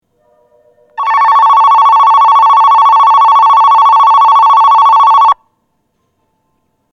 電子電鈴（高音）
発車ベル
4秒鳴動です。ベルが鳴り終わると速攻でドアが閉まります。